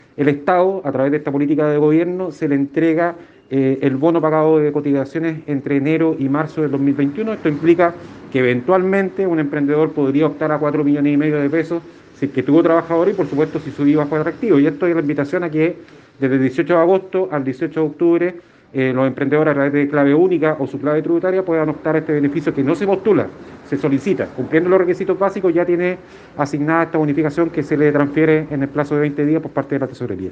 Así lo detalló el seremi de Economía, Francisco Muñoz.